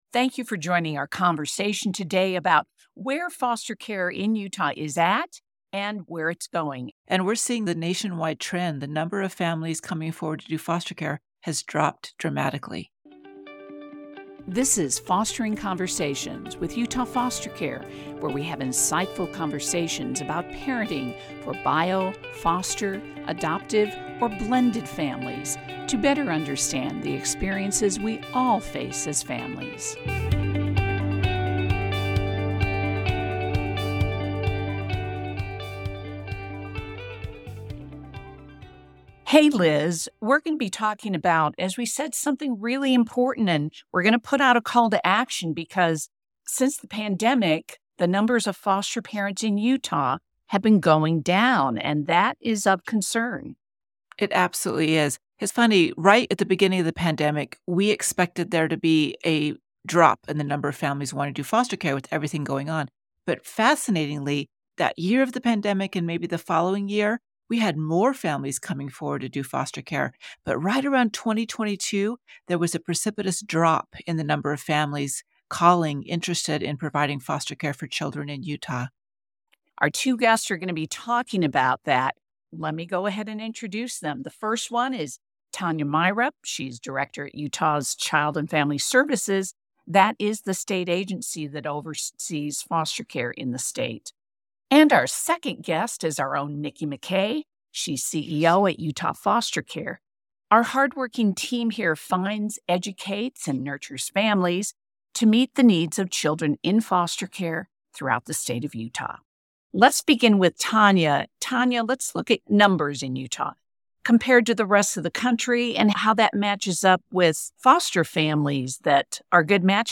Unfortunately, Utah is nowhere near having enough homes, especially those to support kids with special needs. This month is National Foster Care Month, and we talk to two leaders in our state’s foster care community about our shortage of foster parents in Utah.